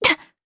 Isaiah-Vox_Attack3_jp.wav